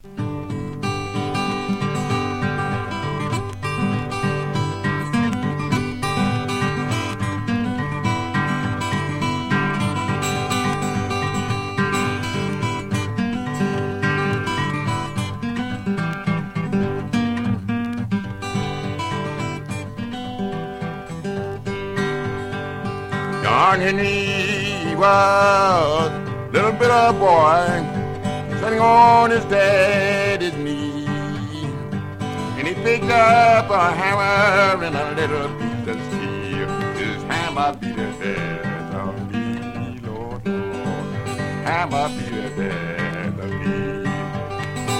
Blues, Country Blues, Ragtime　USA　12inchレコード　33rpm　Stereo